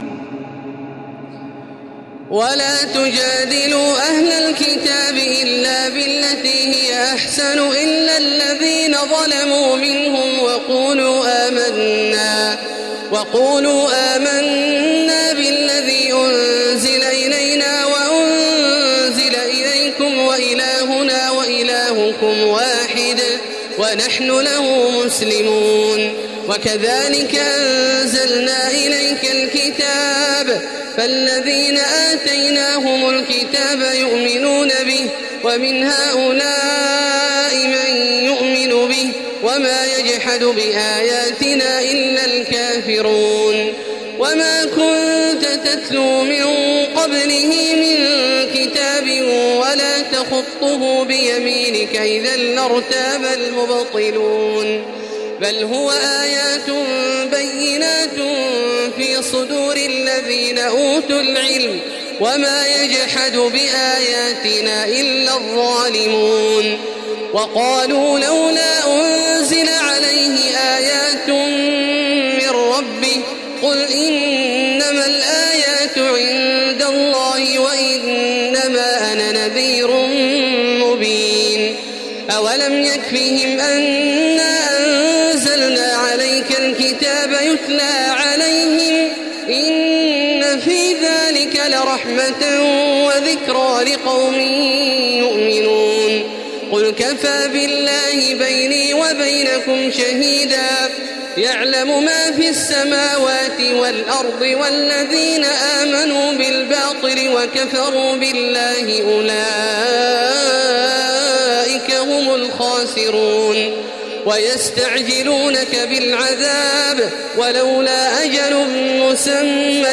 تراويح ليلة 21 رمضان 1435هـ من سور العنكبوت (46-69) و الروم و لقمان Taraweeh 21 st night Ramadan 1435H from Surah Al-Ankaboot and Ar-Room and Luqman > تراويح الحرم المكي عام 1435 🕋 > التراويح - تلاوات الحرمين